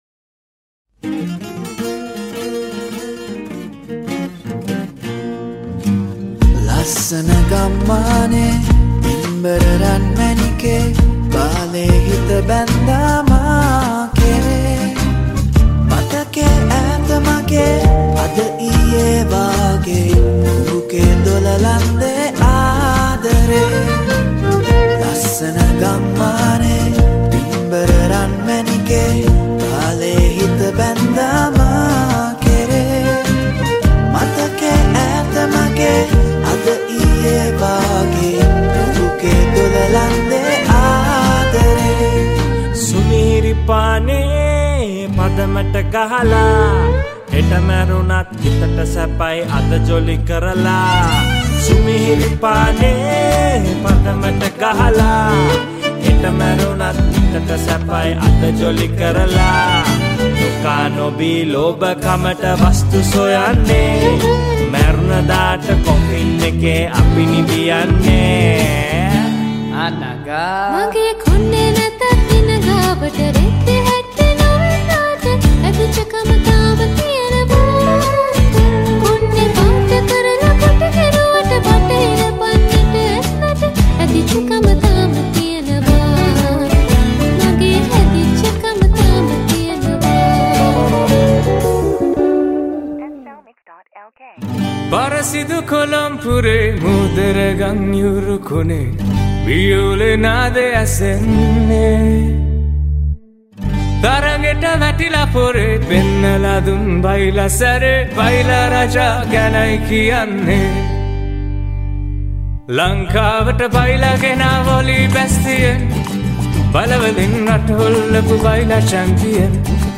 High quality Sri Lankan remix MP3 (6.8).